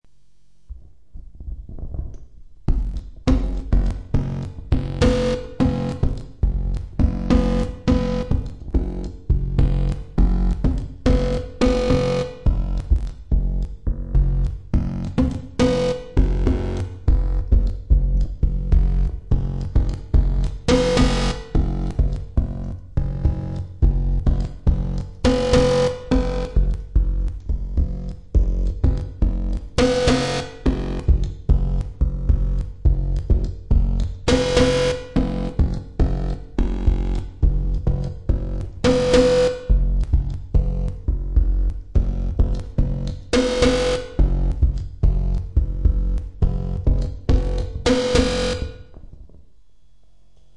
Download Microphone sound effect for free.